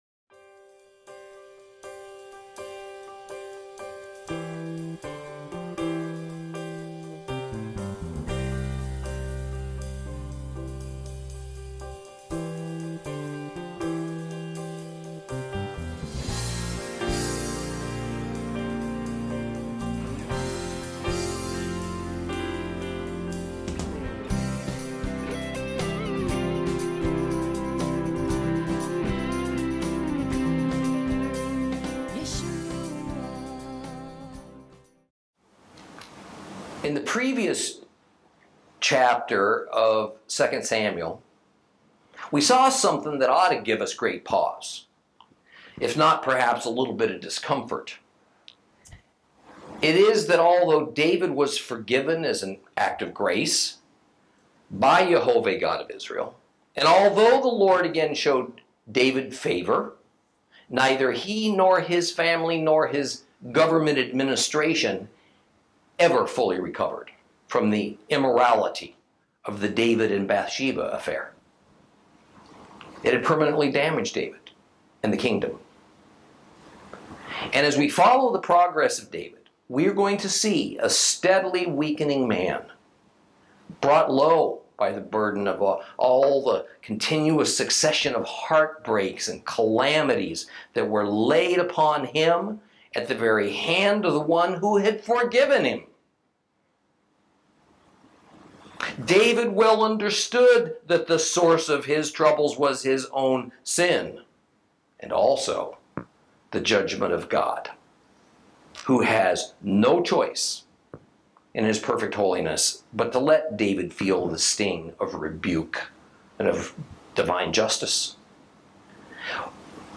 Lesson 20 Ch13 - Torah Class